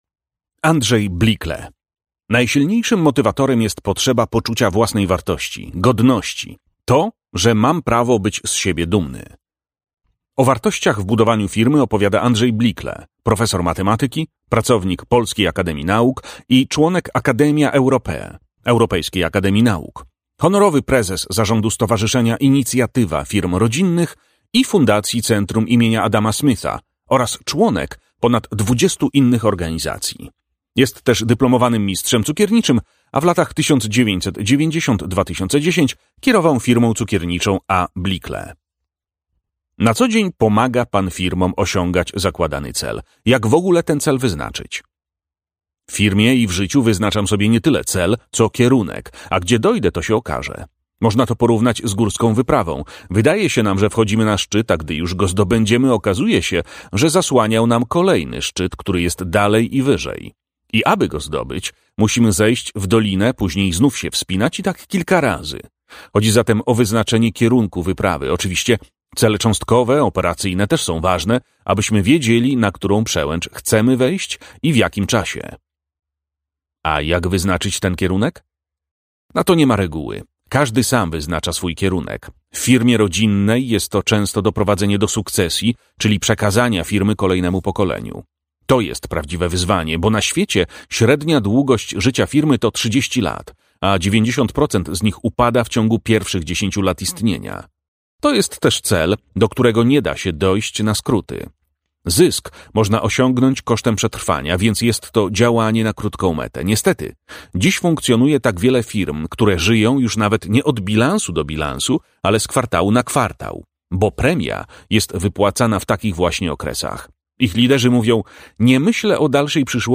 AUDIOBOOK (mp3)